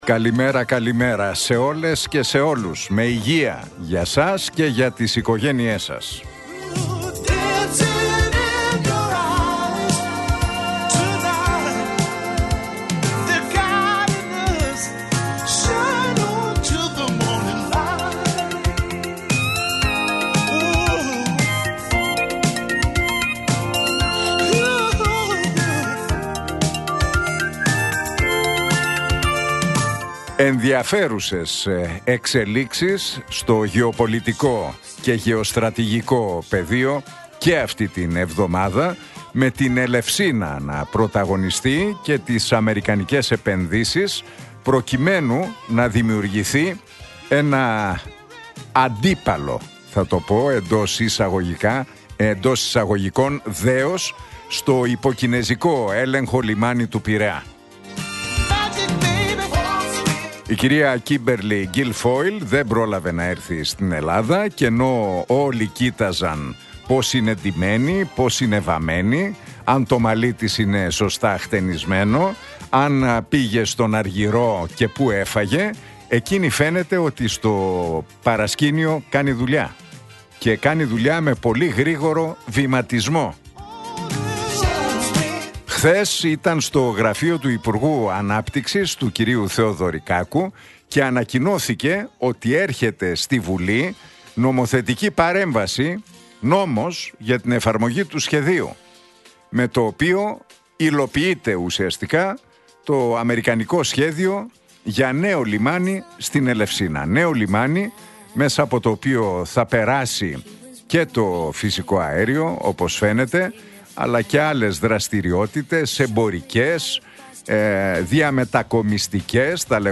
Ακούστε το σχόλιο του Νίκου Χατζηνικολάου στον ραδιοφωνικό σταθμό Realfm 97,8, την Τετάρτη 19 Νοεμβρίου 2025.